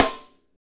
Index of /90_sSampleCDs/Northstar - Drumscapes Roland/DRM_Funk/SNR_Funk Snaresx
SNR FNK S0AL.wav